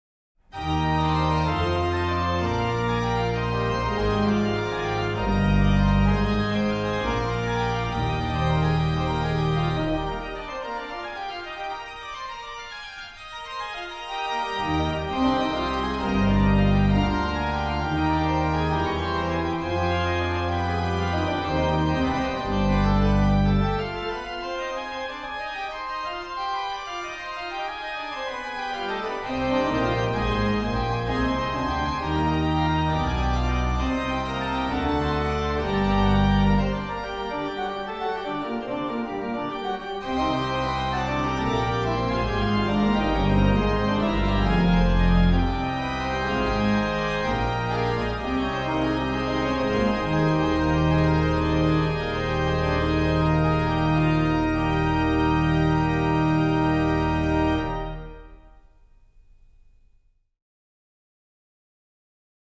Registration   POS: Lged8, Pr4, 2 2/3, Oct2, Mix
PED: Sub16, Viol16, Oct8, Oct4, Tr8